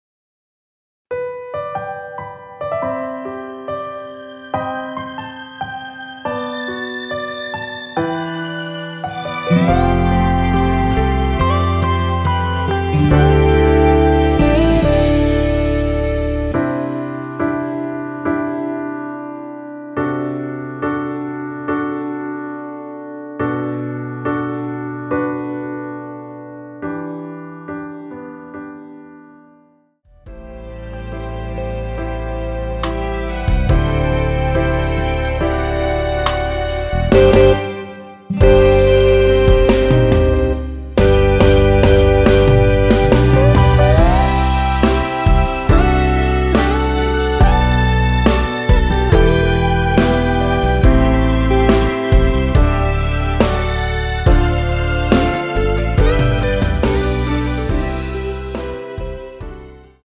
원키에서(+4)올린 (1절앞+후렴)으로 진행되는 MR입니다.
◈ 곡명 옆 (-1)은 반음 내림, (+1)은 반음 올림 입니다.
앞부분30초, 뒷부분30초씩 편집해서 올려 드리고 있습니다.